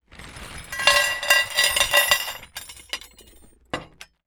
Metal_47.wav